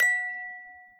mailalert.mp3